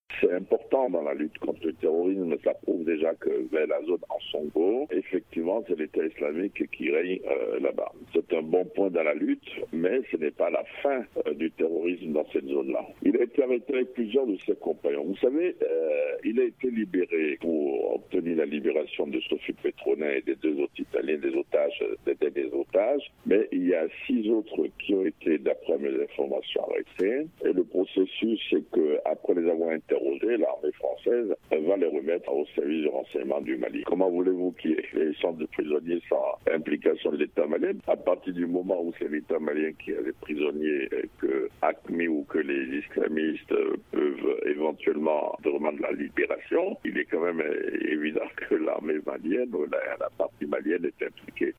REACTION-ANALYSTE-FR.mp3